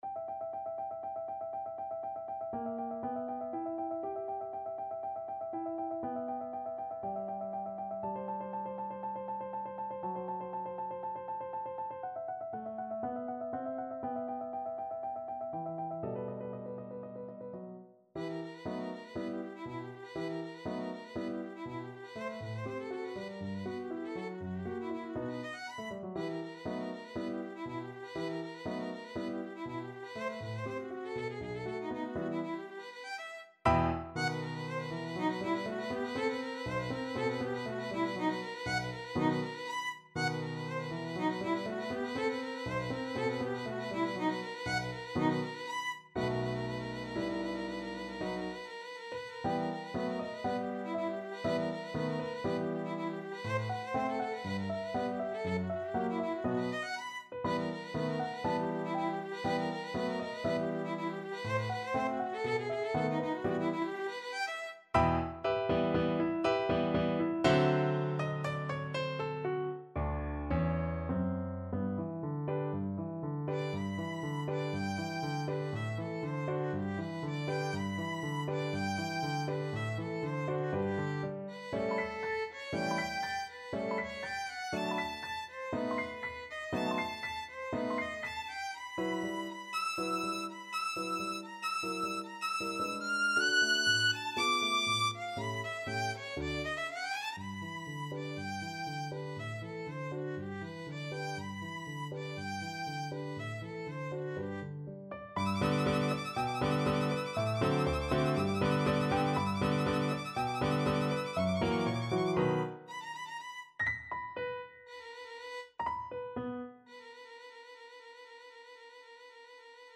Violin
E minor (Sounding Pitch) (View more E minor Music for Violin )
= 120 Allegro molto vivace (View more music marked Allegro)
2/4 (View more 2/4 Music)
Classical (View more Classical Violin Music)
kohler_papillon_VLN.mp3